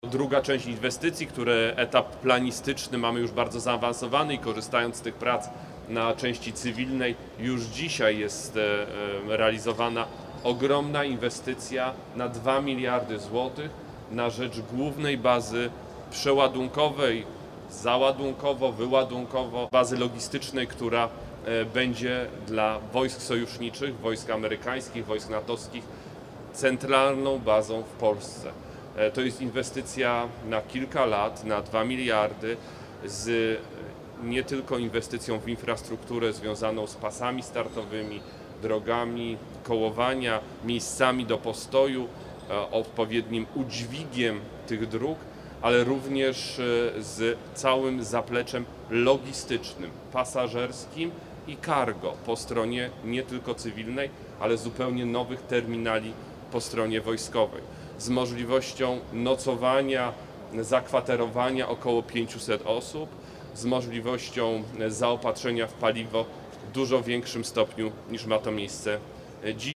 Władysław Kosiniak-Kamysz, Minister Obrony Narodowej podkreślił, że nie ma bezpieczeństwa Polski bez silnych regionów i bezpieczeństwa w tych regionach.